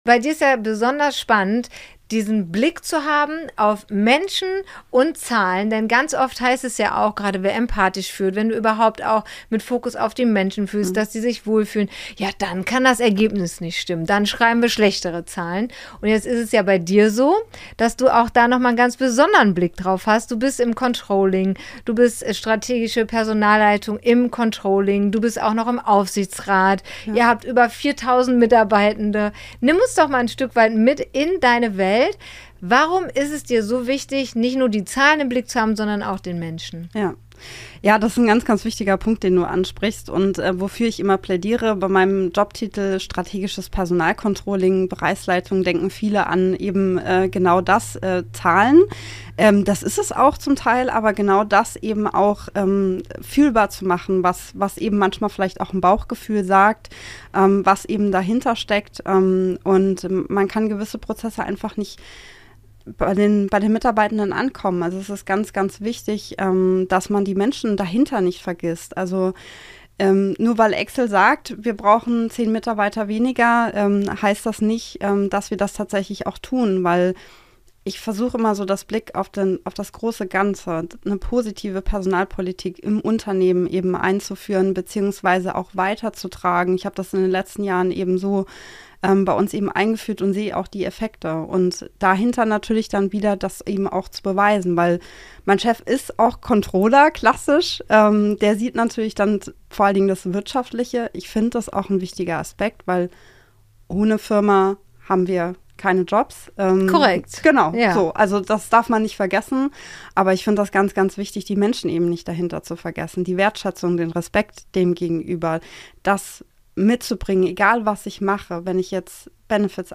Gedreht haben wir im Eventflugzeug auf dem euronova CAMPUS in Hürth.